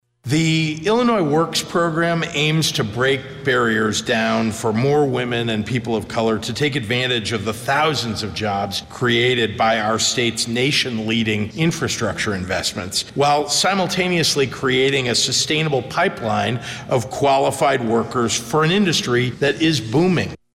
Governor Pritzker says it will help train students for jobs that are currently available and those expected in the future…